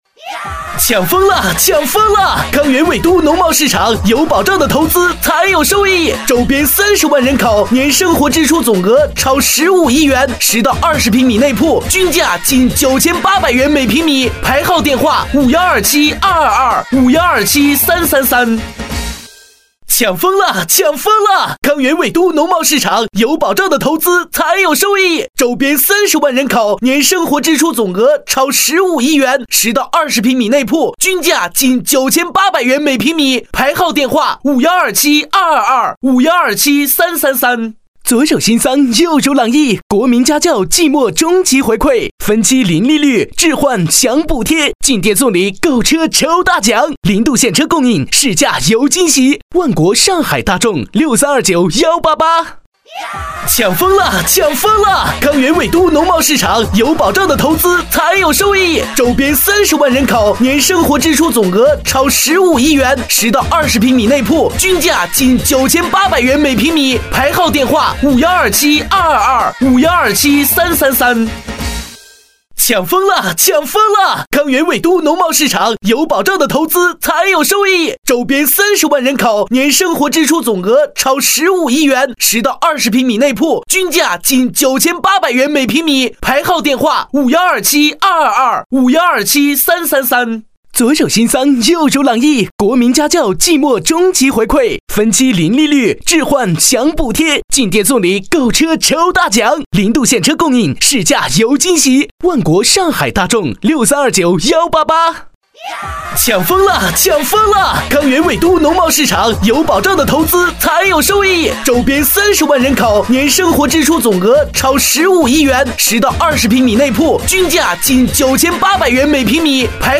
国语青年沉稳 、娓娓道来 、积极向上 、男课件PPT 、40元/分钟男B073 国语 男声 产品介绍课件-华美风幕-柜子说明解说-成熟稳重 沉稳|娓娓道来|积极向上 - 样音试听_配音价格_找配音 - voice666配音网